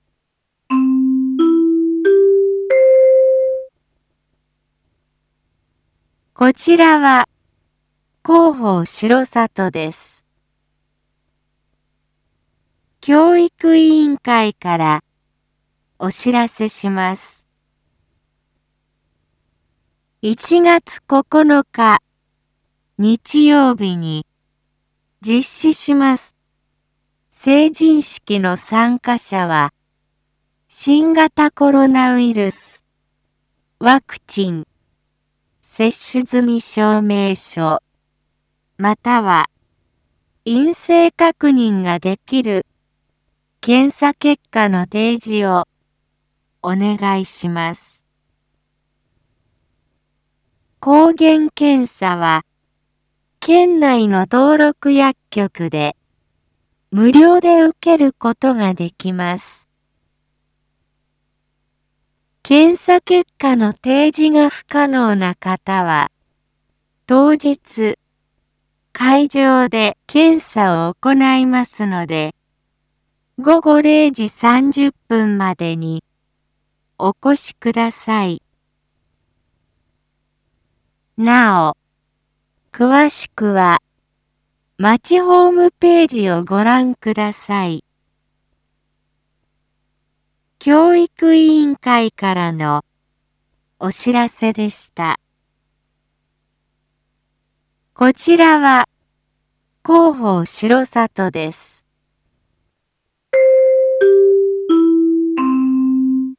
Back Home 一般放送情報 音声放送 再生 一般放送情報 登録日時：2022-01-07 19:01:48 タイトル：R4.1.7 １９時 インフォメーション：こちらは広報しろさとです。